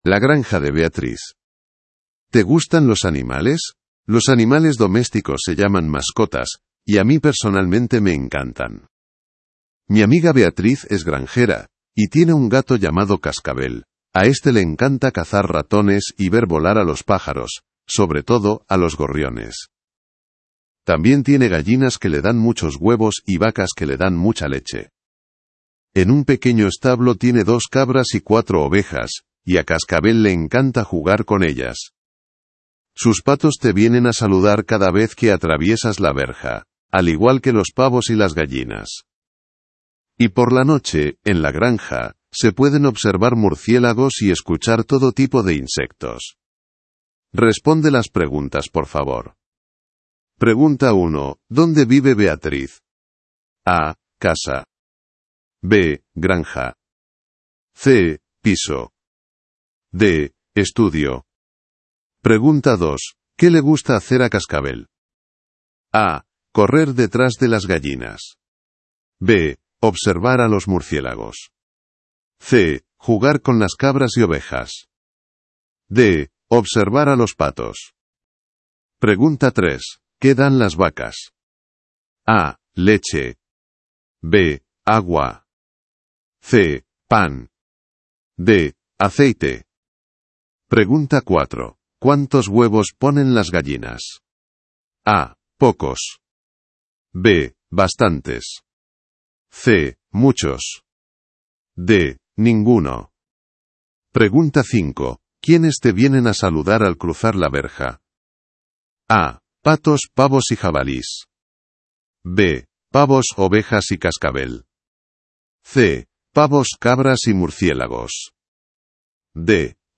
Hiszpania